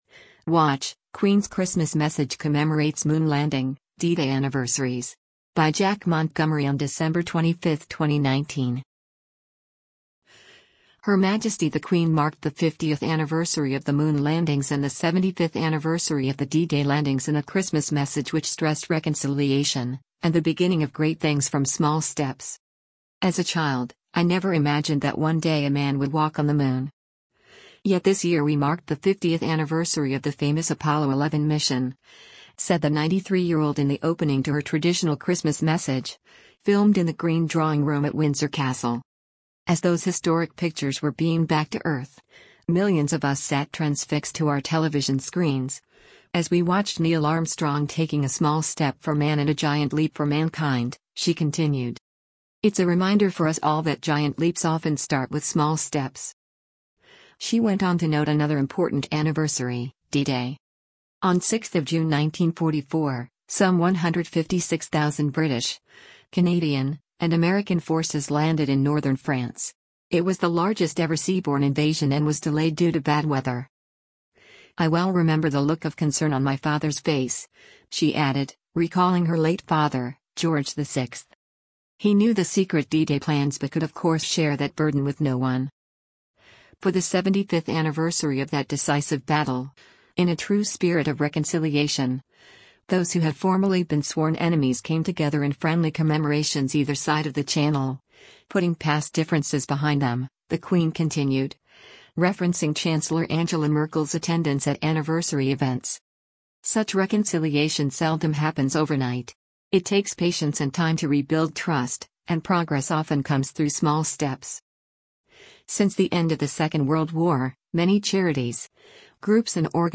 “As a child, I never imagined that one day a man would walk on the moon. Yet this year we marked the 50th anniversary of the famous Apollo 11 mission,” said the 93-year-old in the opening to her traditional Christmas message, filmed in the Green Drawing Room at Windsor Castle.